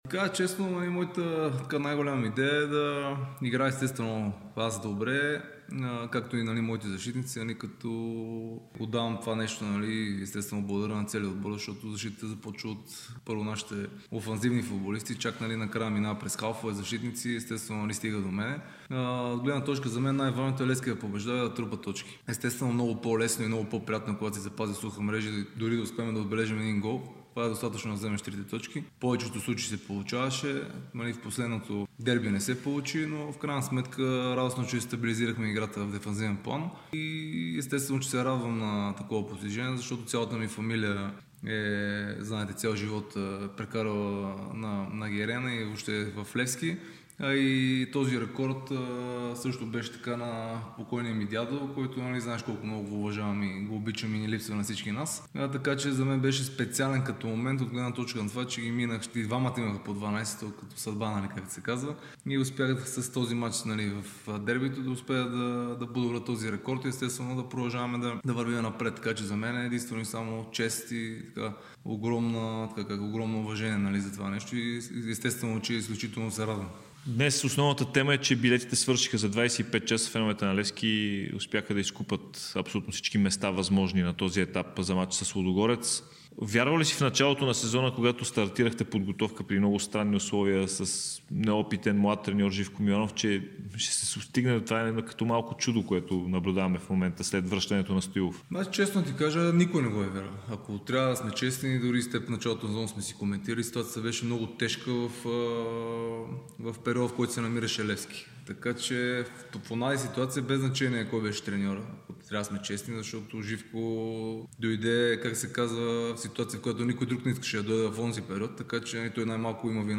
Вратарят на Левски Николай Михайлов даде обширно интервю пред Дарик и dsport, в което говори за предстоящия реванш от 1/2-финалите за Купата срещу Лудогорец, преломният момент при "сините", постижението му от 13 сухи мрежи през сезона, както и неговият договор с клуба.